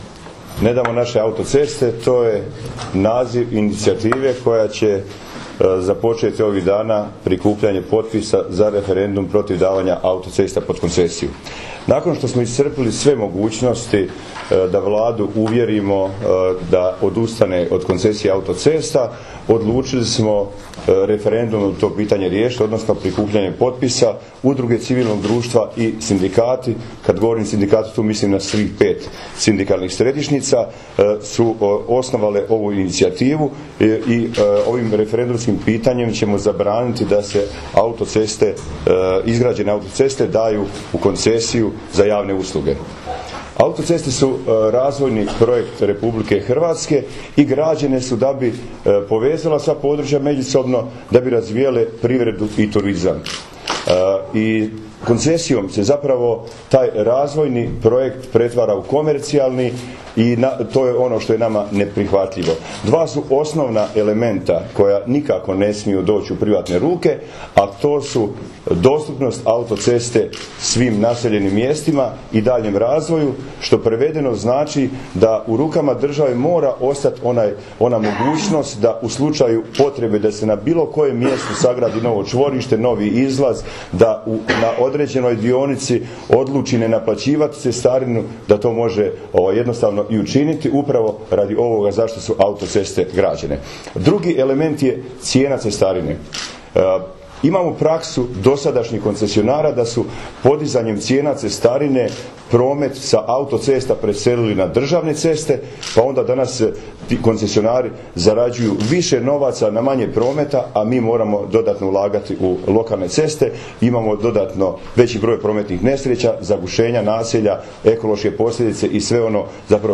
Poslušajte audio zapis konferencije: